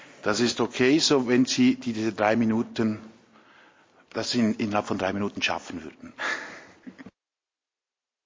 21.9.2021Wortmeldung
Session des Kantonsrates vom 20. bis 22. September 2021